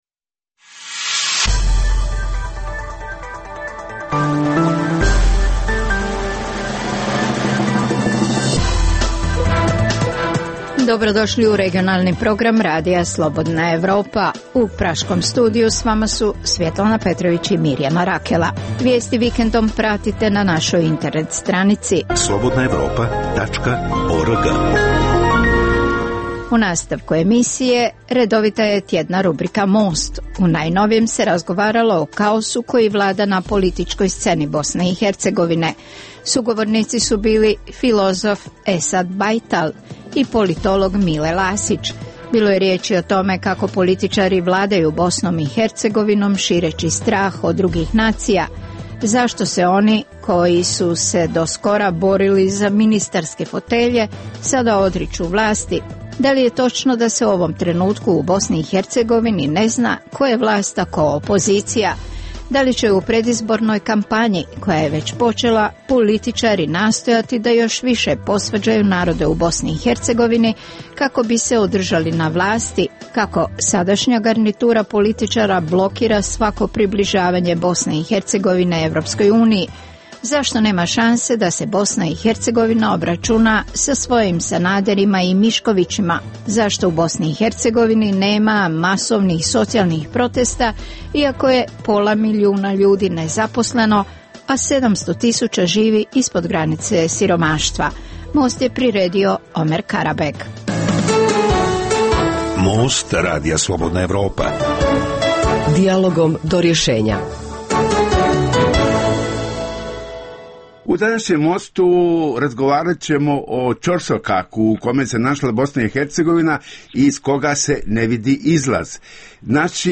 Emisija o dešavanjima u regionu (BiH, Srbija, Kosovo, Crna Gora, Hrvatska) i svijetu.